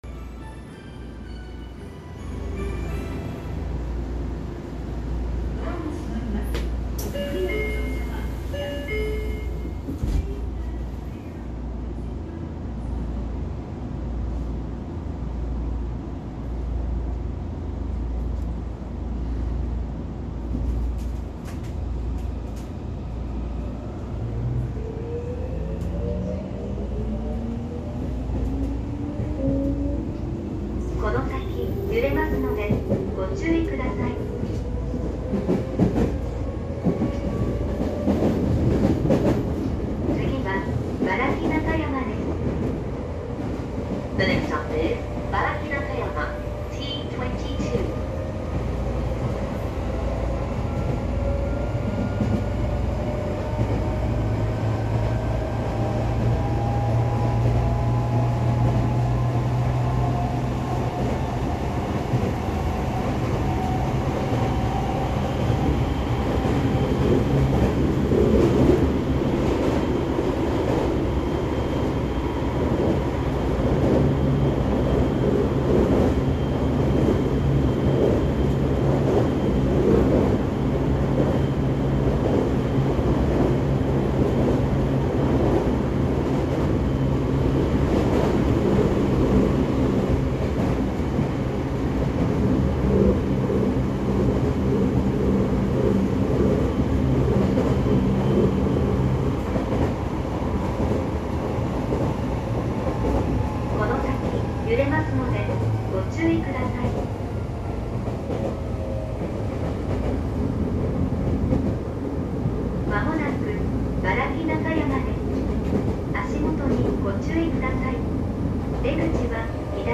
07系三菱IGBT車走行音
東京メトロ（当時の営団地下鉄）では他社がGTO車を導入していた頃に既に積極的にIGBT車を導入していたのが大きな特徴で、全国的に見れば珍しい非常に初期のIGBTのモーター音を聞くことが出来ます。
07mmc_myoden-baraki.mp3